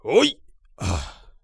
ZS瞬间起身2.wav
人声采集素材/男3战士型/ZS瞬间起身2.wav